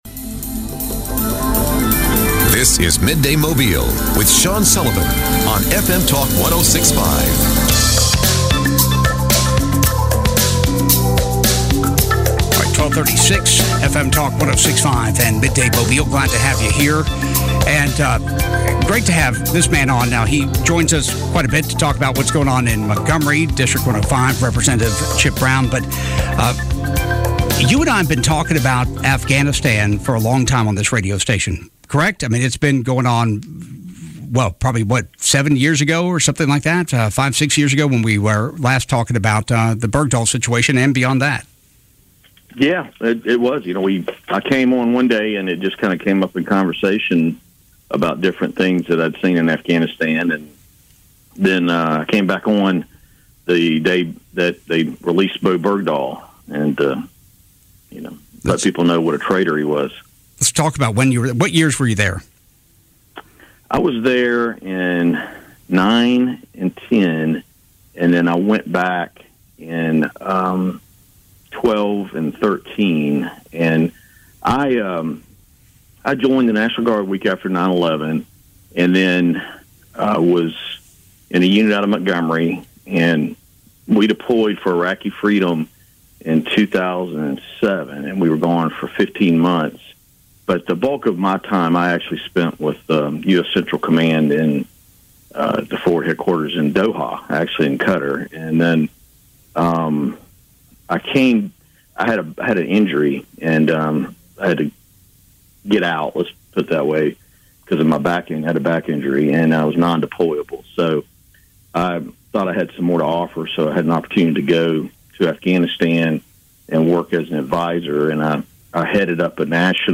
State Representative Chip Brown discusses his time fighting overseas in Afghanistan and what the withdrawal means for America and the Afghans he fought for and alongside.